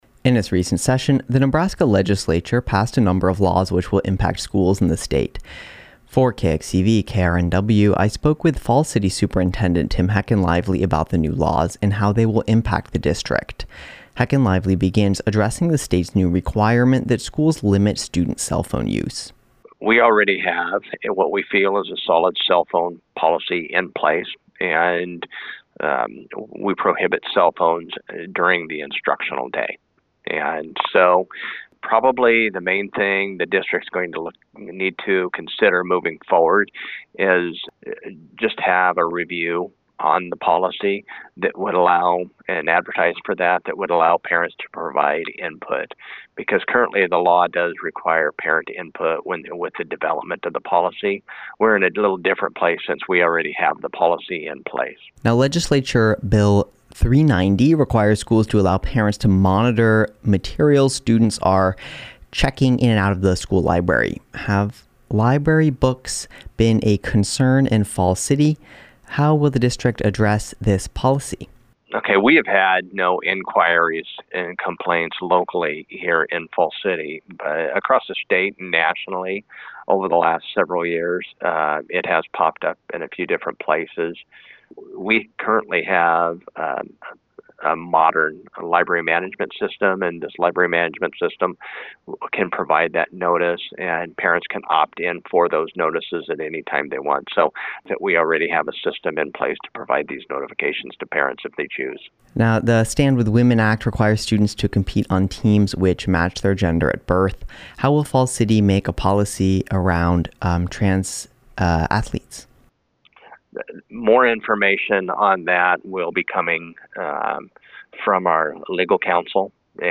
News Brief
This interview has been edited for brevity.